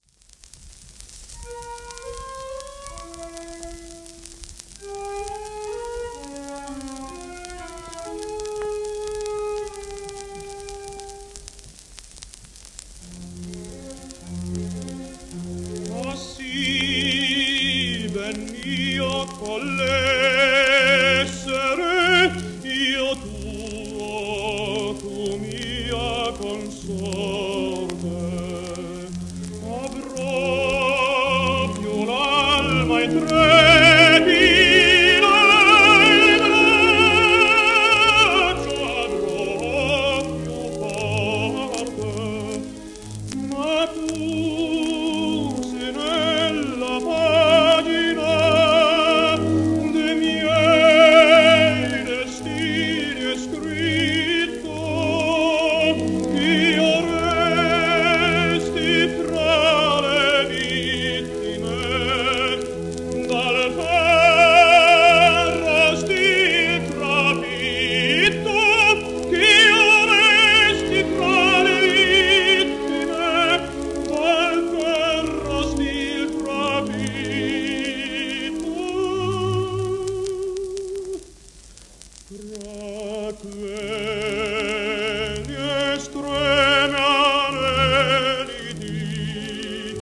w/オーケストラ